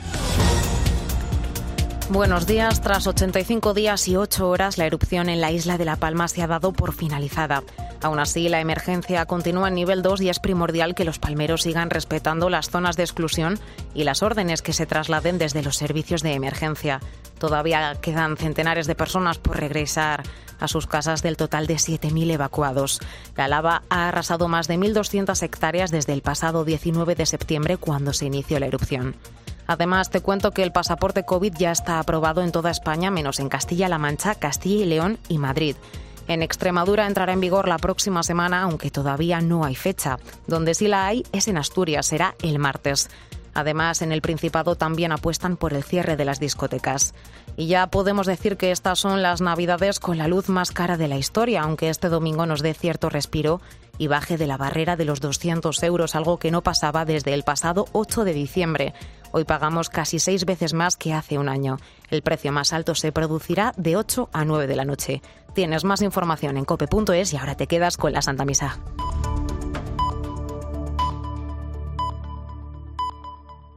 AUDIO: Boletín de noticias de COPE del 26 de diciembre de 2021 a las 09.00 horas